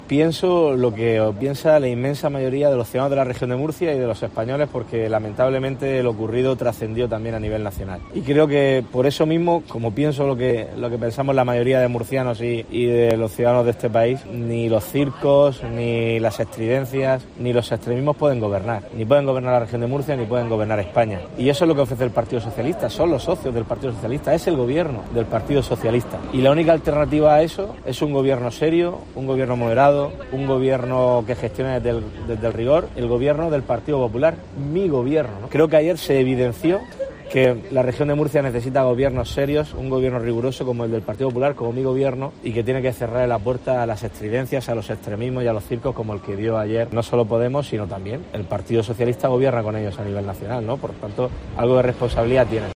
López Miras ha destaca, en un acto celebrado en Mazarrón, que "hoy, más que nunca, se hace necesario unir el voto en torno al PP, que representa el partido de la gestión, la normalidad, la estabilidad", porque, si lo dividimos, Sánchez y sus socios de Podemos, serán quienes ganen"